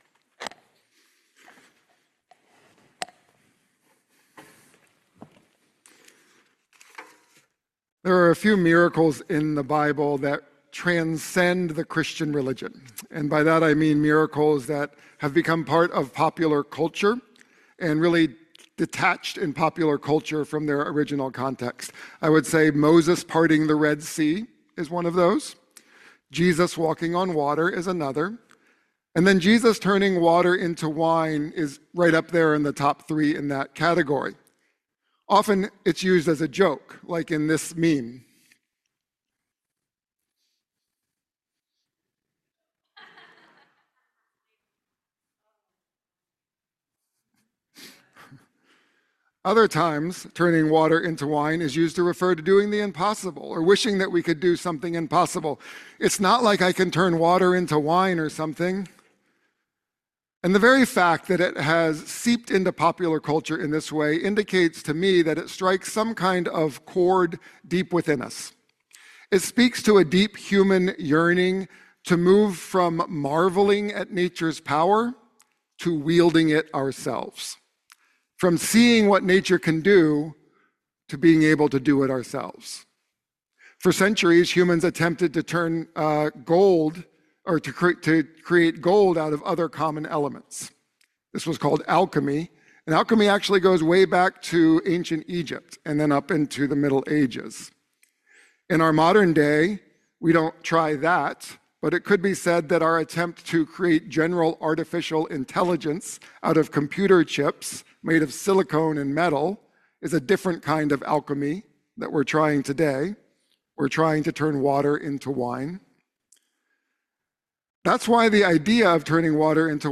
sermon-11126.mp3